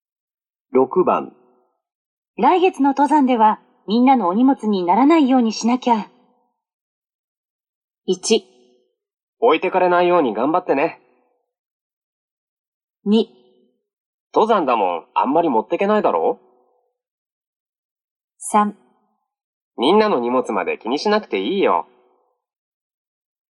类别: N1听力库